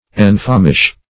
Enfamish \En*fam"ish\